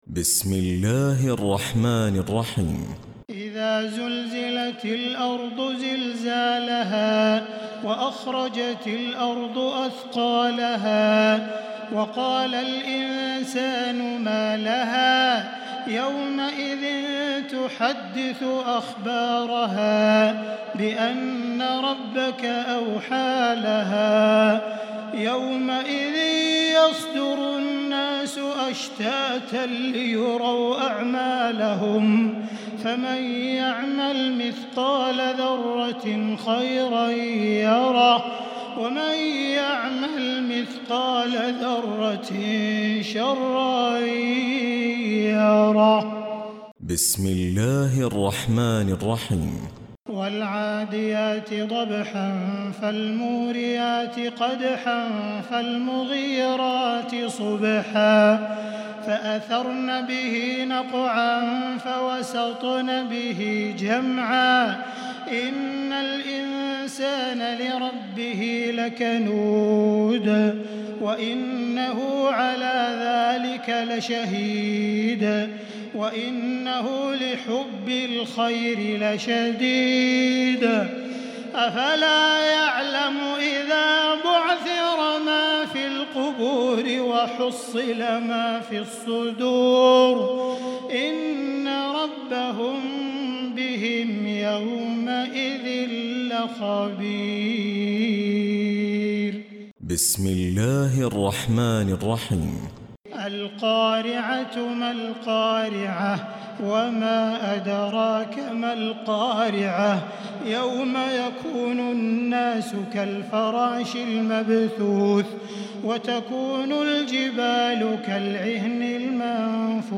تراويح ليلة 29 رمضان 1438هـ من سورة الزلزلة الى الناس Taraweeh 29 st night Ramadan 1438H from Surah Az-Zalzala to An-Naas > تراويح الحرم المكي عام 1438 🕋 > التراويح - تلاوات الحرمين